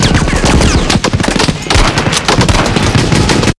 sk12_shot.wav